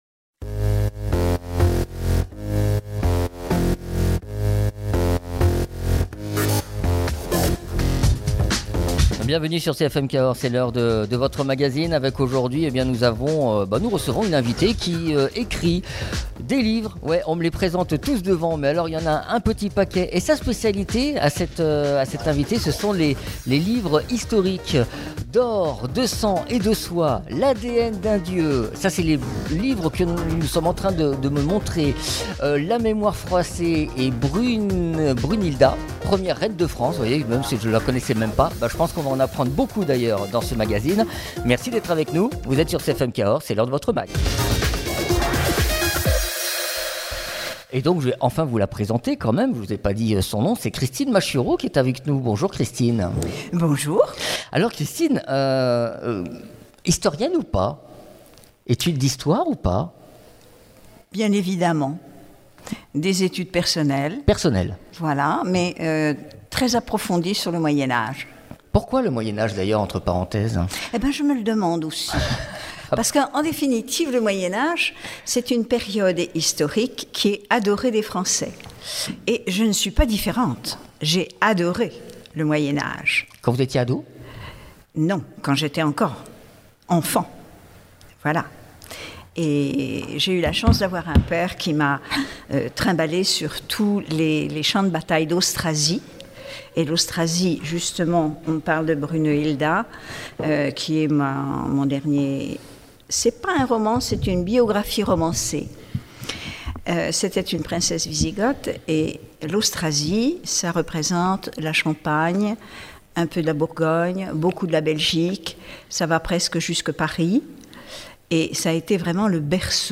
Des romans basés sur des recherches historiques. Emission enregistré au musée Armand Viré de Luzech (Lot)